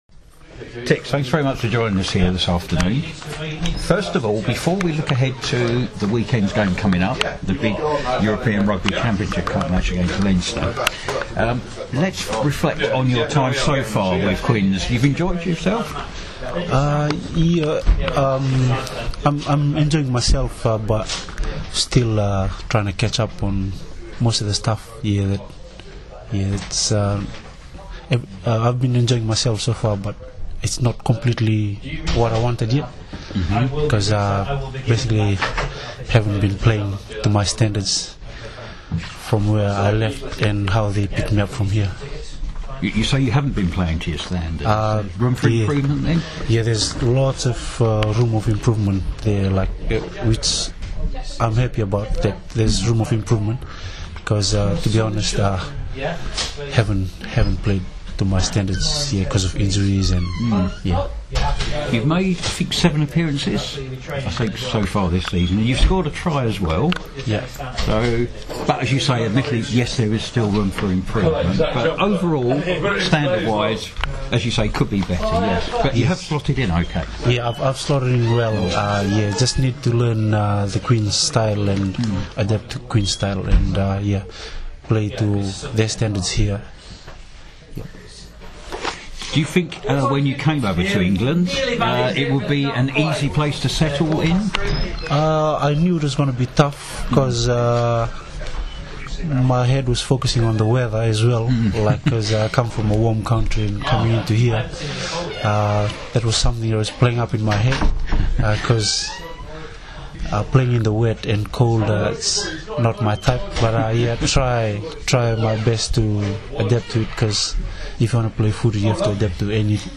at Quins training ground on December 2nd